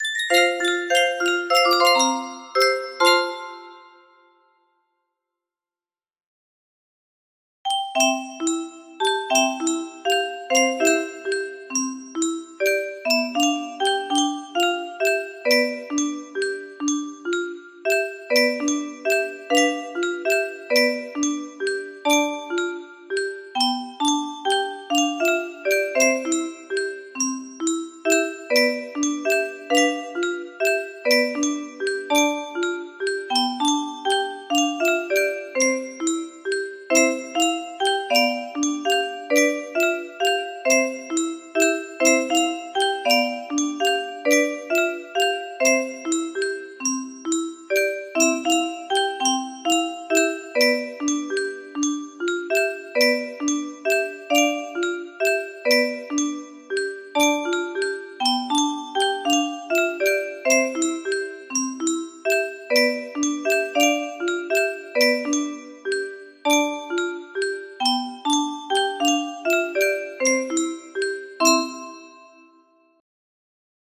tu scendi dalle stelle music box melody